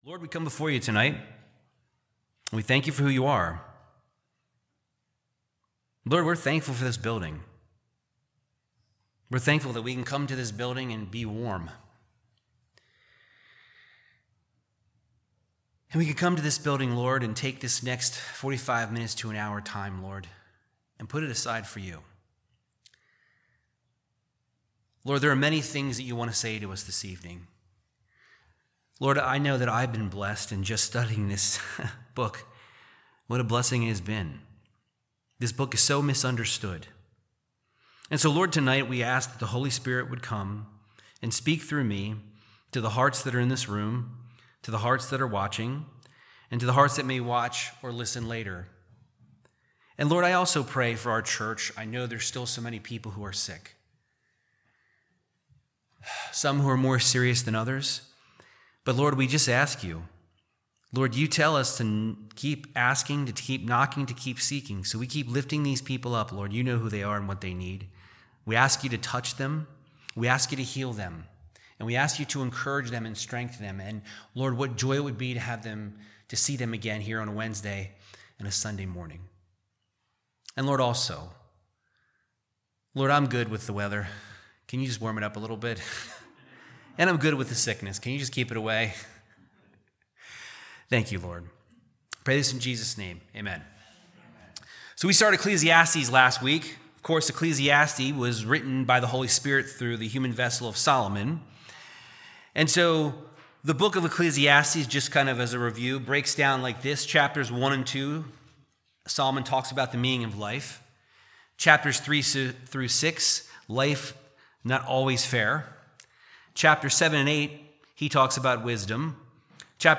Verse by verse Bible Teaching of Ecclesiastes 2